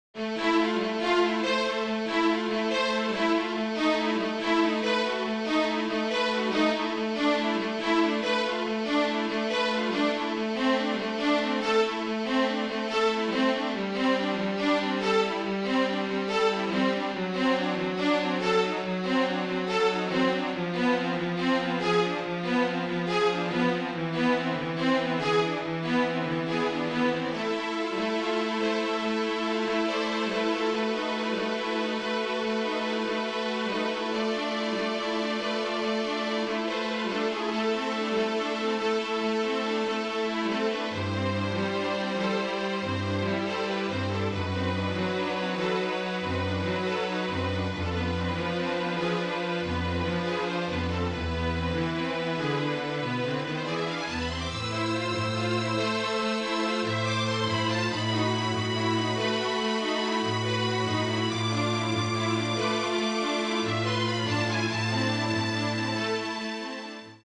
(string quartet)